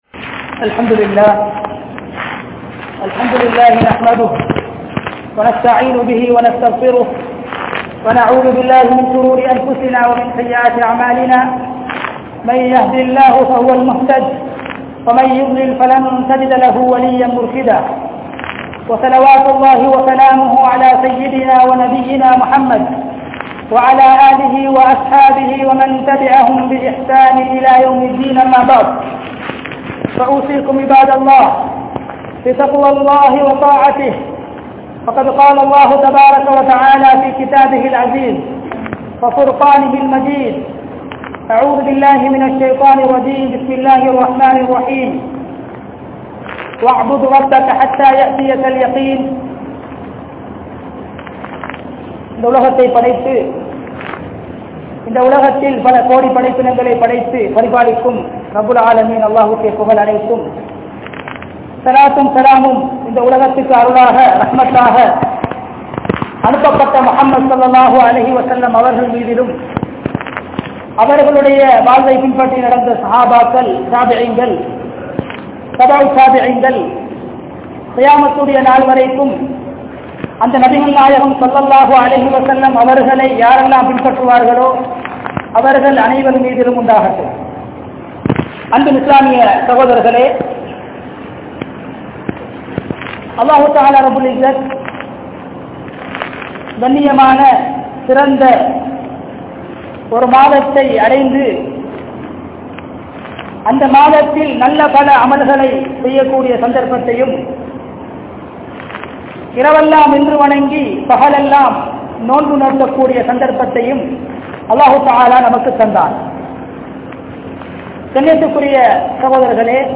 Isthiqamath | Audio Bayans | All Ceylon Muslim Youth Community | Addalaichenai